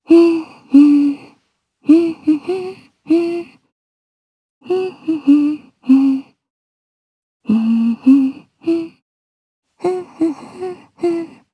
Lewsia_B-Vox_Hum_jp.wav